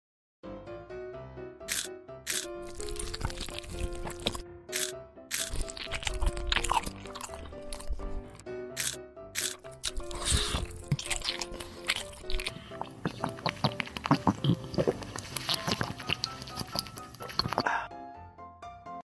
Asmr Blue Food Mukbang _ sound effects free download
Asmr Eating Sounds